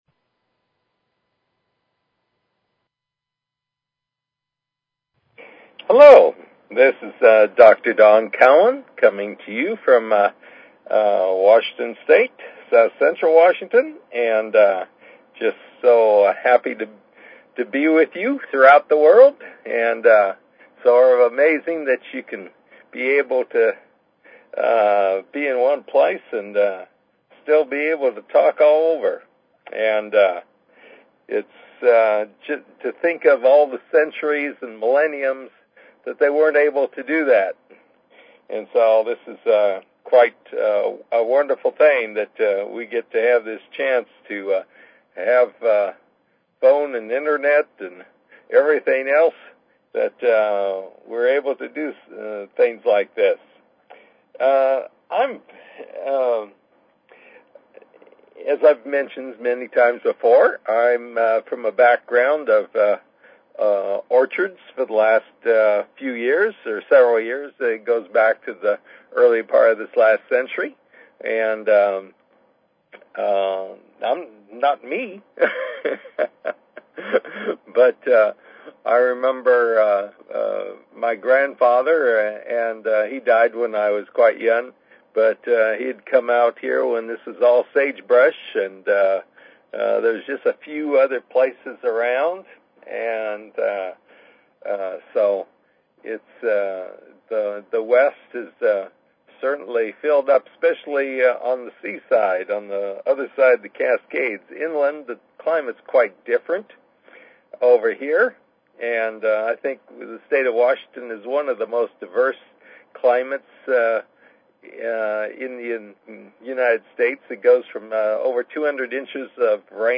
Talk Show Episode, Audio Podcast, New_Redeaming_Spirituality and Courtesy of BBS Radio on , show guests , about , categorized as